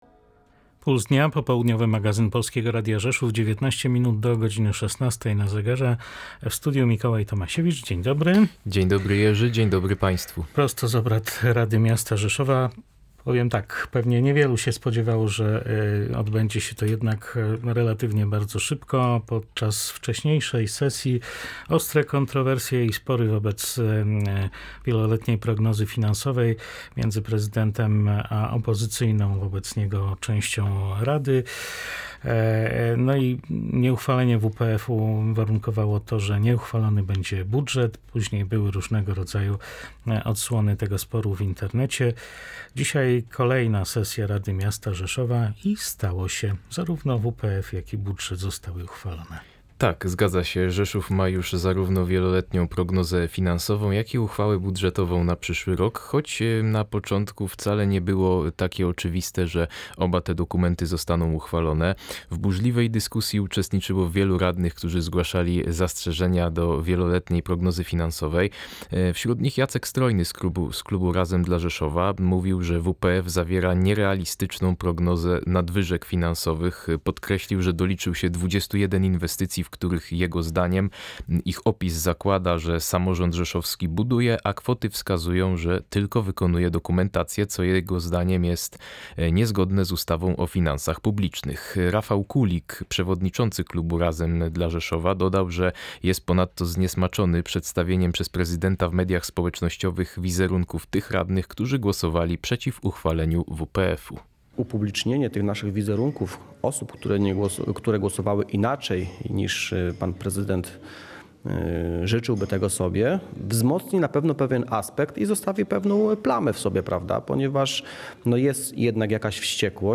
Tasma-z-sesji-Rady-Rzeszowa.mp3